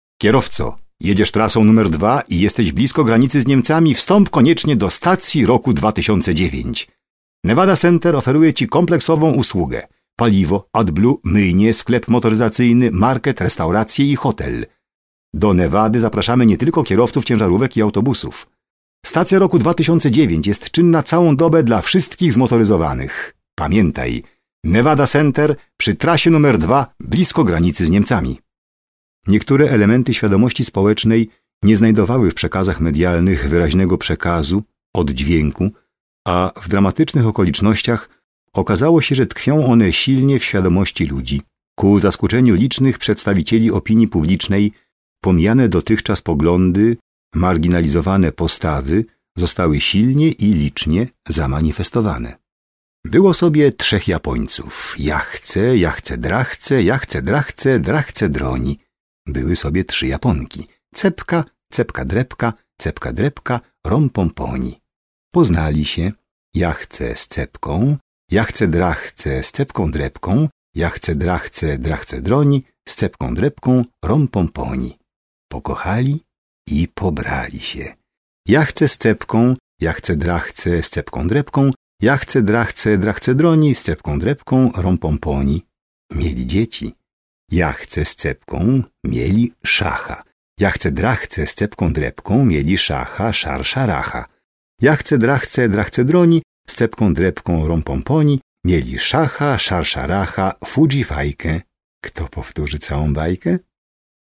Radio and TV voice artist with a lyrical, deep voice timbre. His specialty is feuilletons, literary prose and poetry.